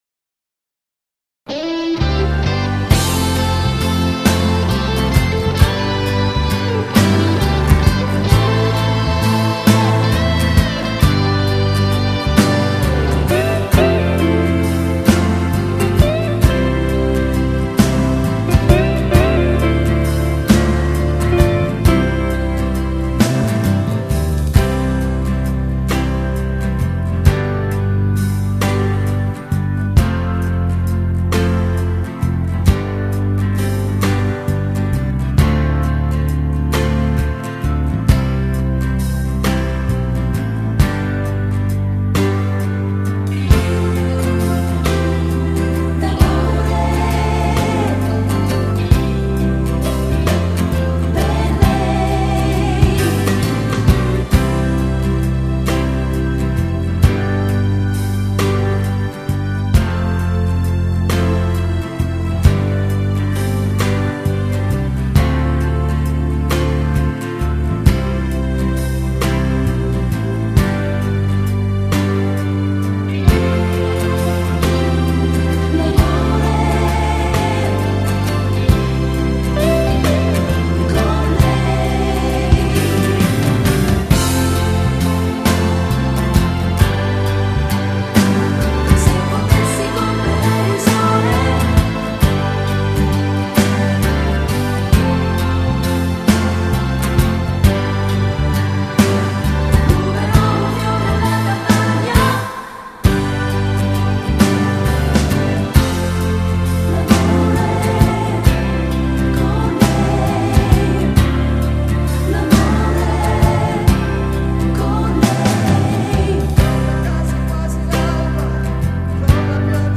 Genere: Blues Terzinato
Scarica la Base Mp3 (3,50 MB)